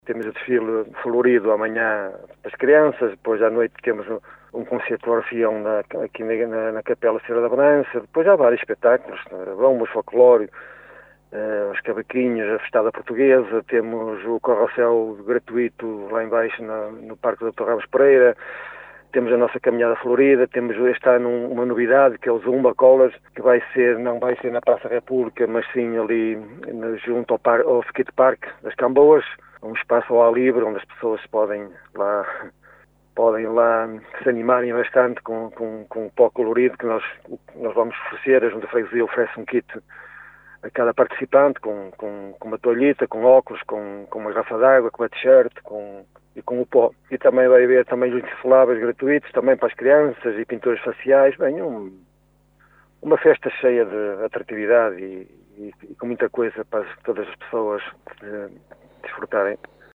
Carlos Castro, presidente da junta de freguesia de Vila Praia de Âncora, está no local a ultimar os preparativos desta grande festa, como revelou em entrevista ao Jornal C – O Caminhense.